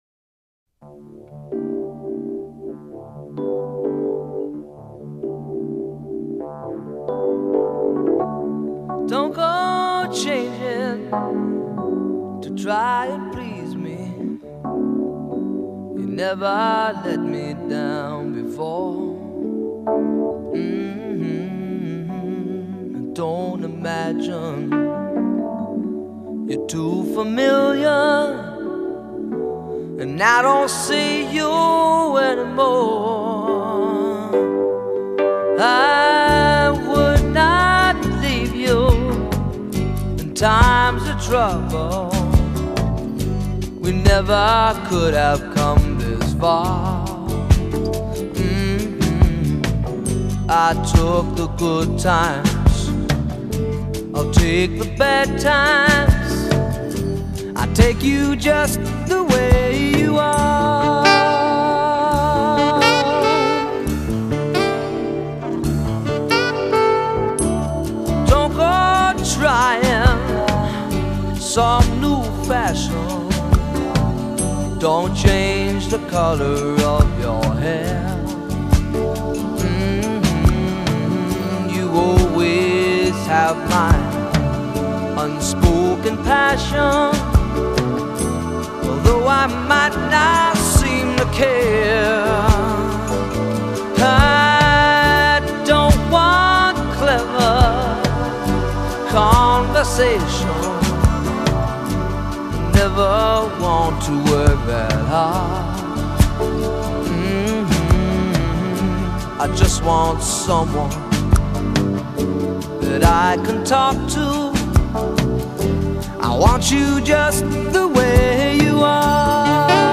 Solo Recorder